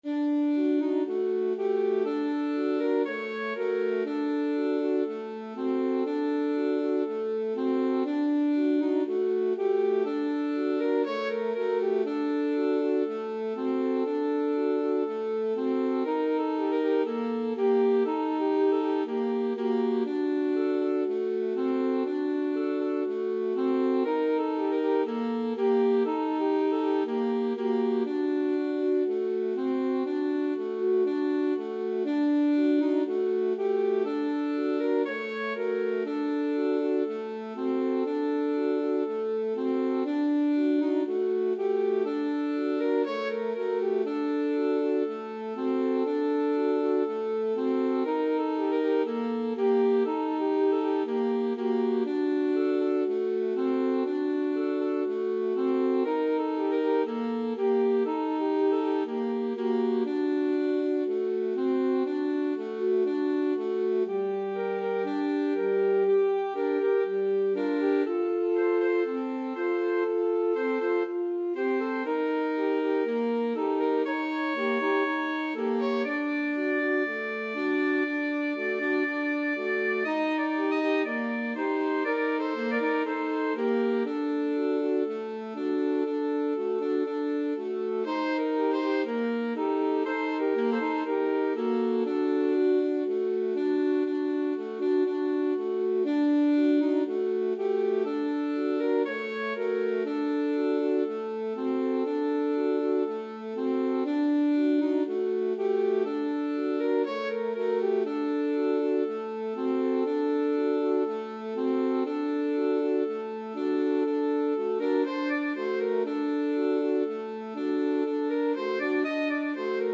Free Sheet music for Alto Sax Trio
Traditional Music of unknown author.
4/4 (View more 4/4 Music)
Bb major (Sounding Pitch) F major (French Horn in F) (View more Bb major Music for Alto Sax Trio )
Pop (View more Pop Alto Sax Trio Music)
world (View more world Alto Sax Trio Music)